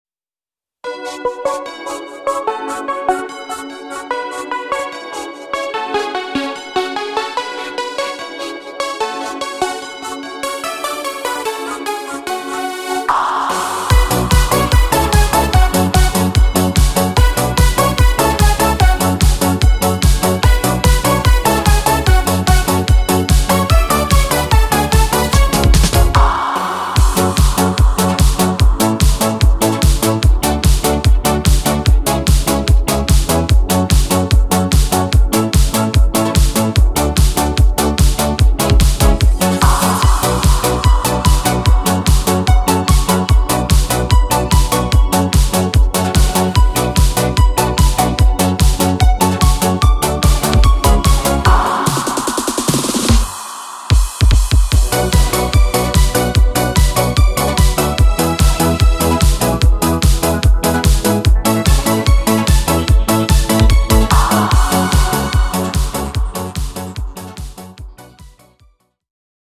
Wersja instrumentalna
Disco Polo